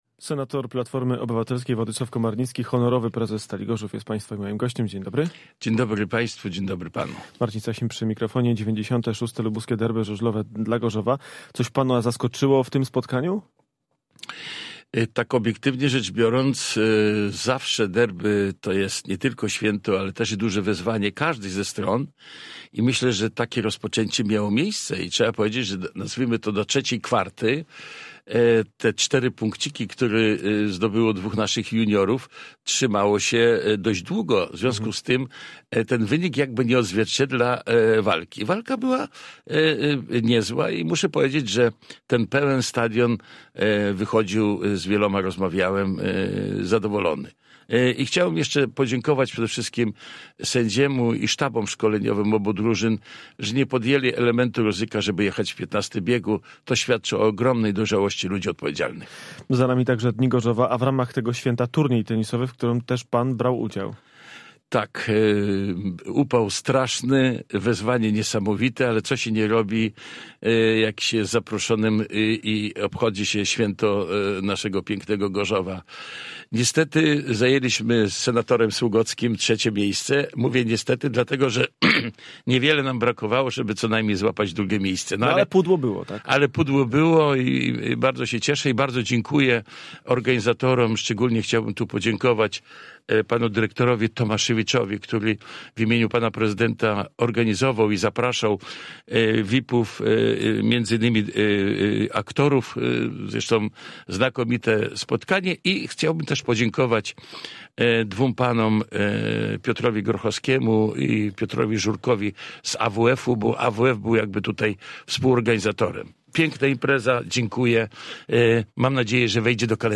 Z senatorem PO rozmawia